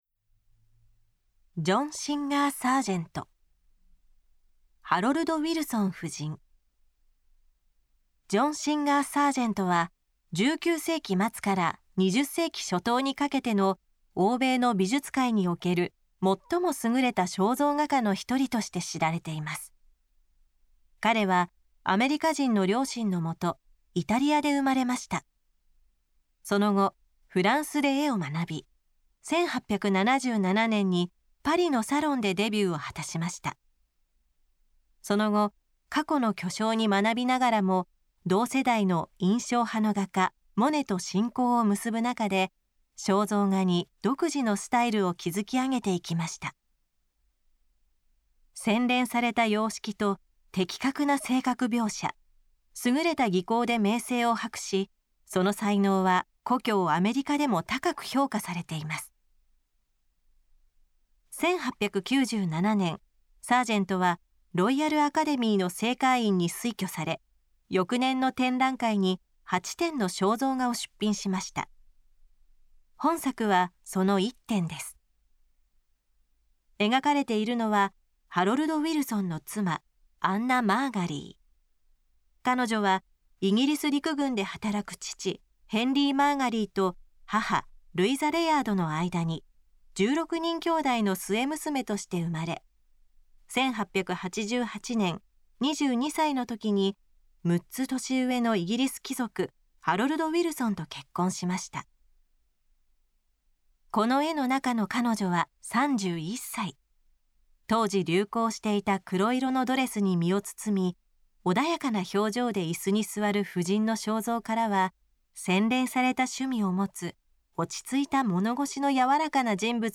作品詳細の音声ガイドは、すべて東京富士美術館の公式ナビゲーターである、本名陽子さんに勤めていただいております。